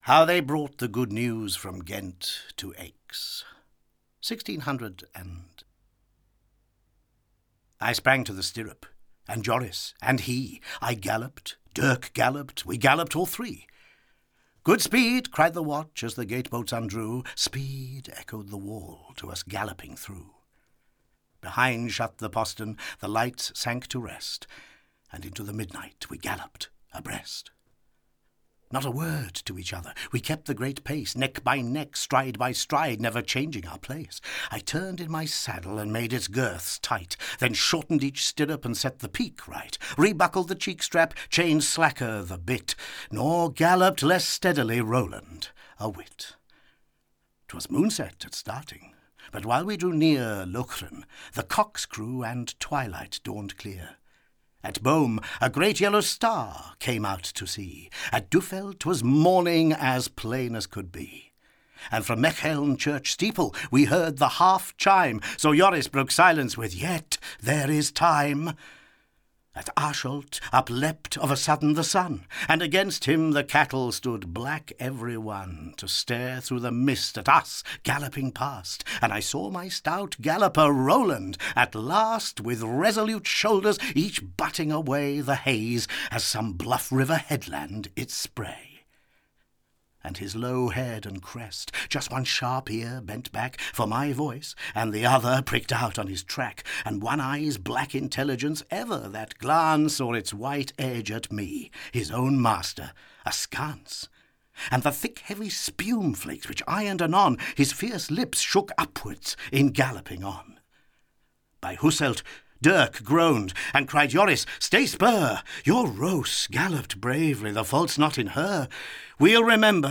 Audio knihaThe Great Poets – Robert Browning (EN)
Ukázka z knihy